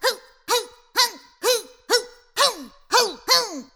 yell-hah-64bpm-0.5beats.ogg